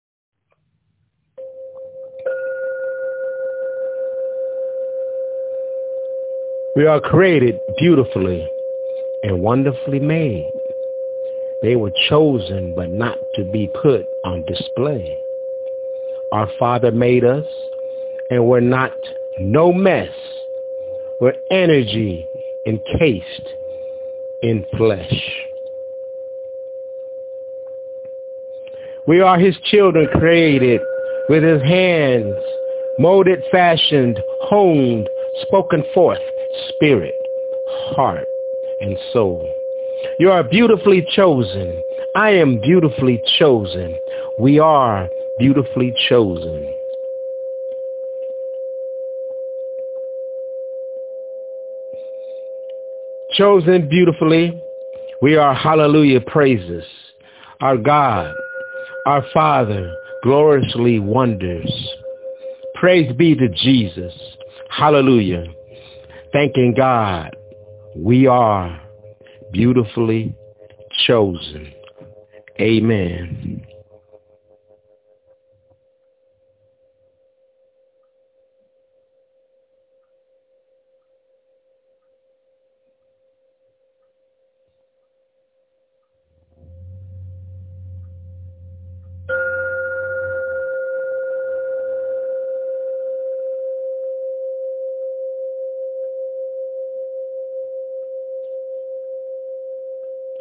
Beautifully Chosen--My Spokenword